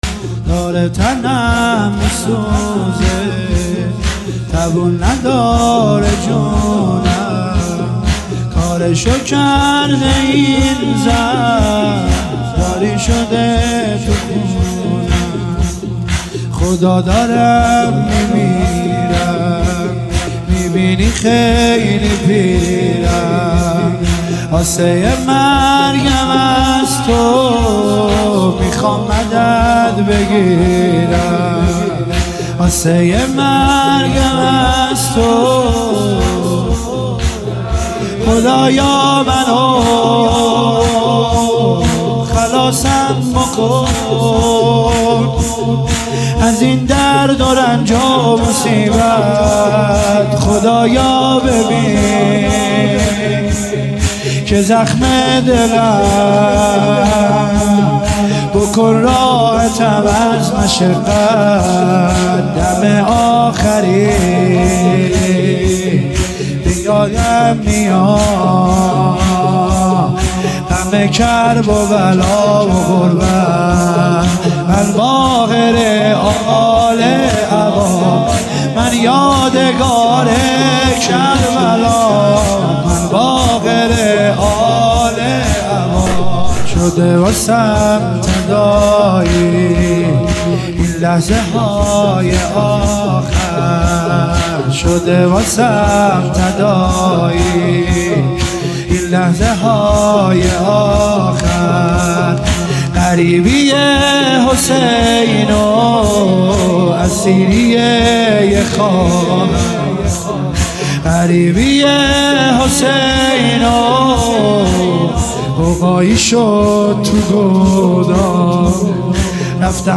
مداحی «داره تنم میسوزه